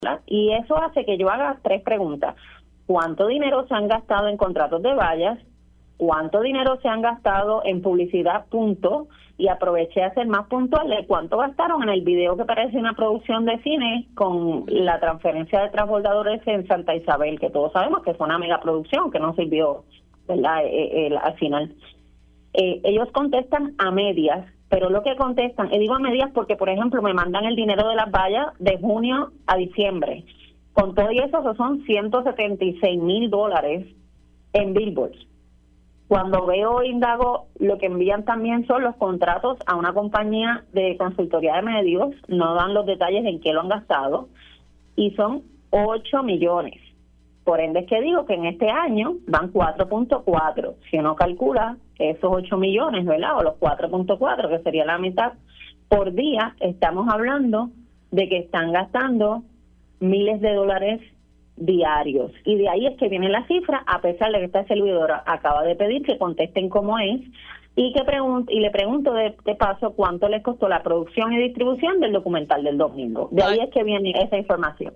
214-ADA-ALVAREZ-SENADORA-PPD-SOLICITA-INFO-A-LUMA-ENERGY-SOBRE-GASTO-EN-PUBLICIDAD-ENTIENDE-GASTAN-4.4-MILLONES.mp3